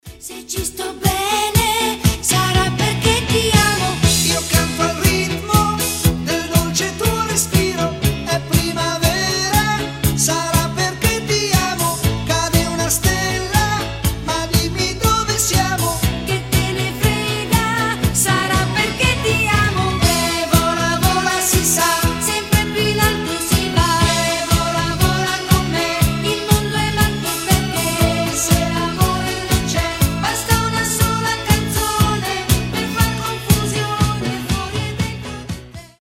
Итало диско Ретро